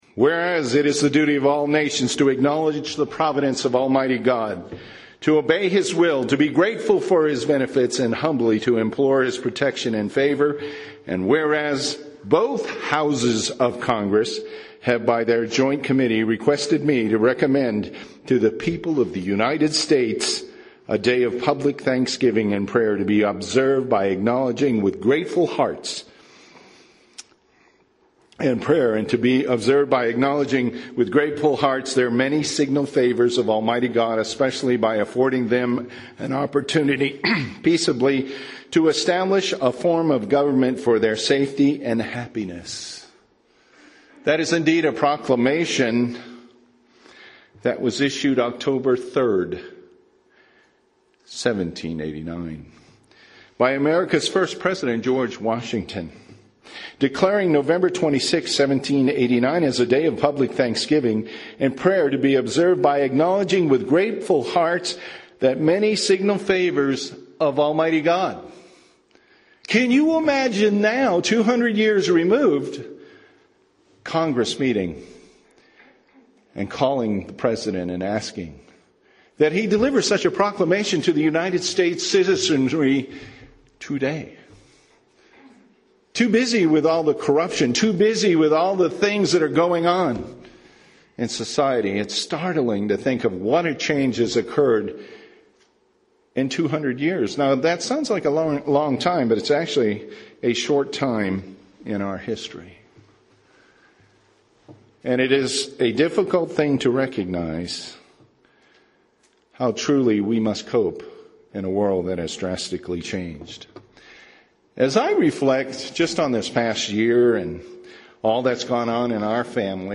Given in Albuquerque, NM